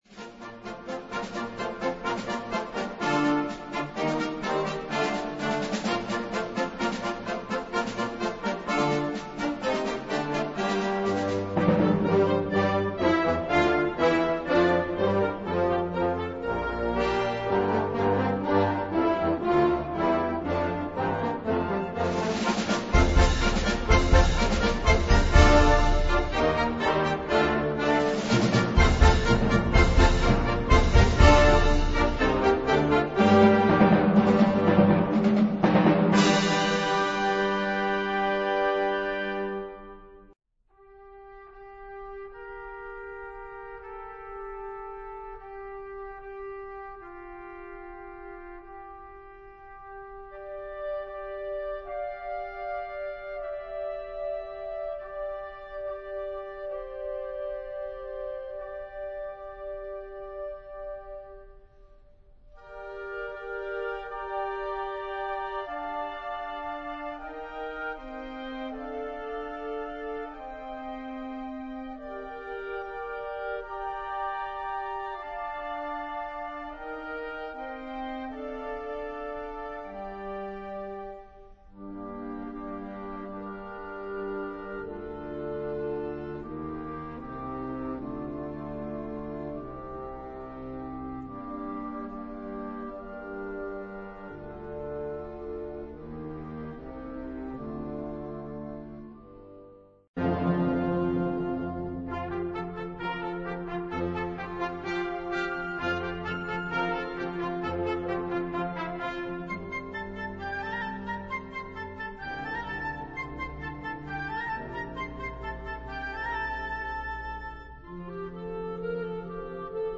Kategorie Blasorchester/HaFaBra
Unterkategorie Konzertmusik
Besetzung Ha (Blasorchester)
Tänze und Gesänge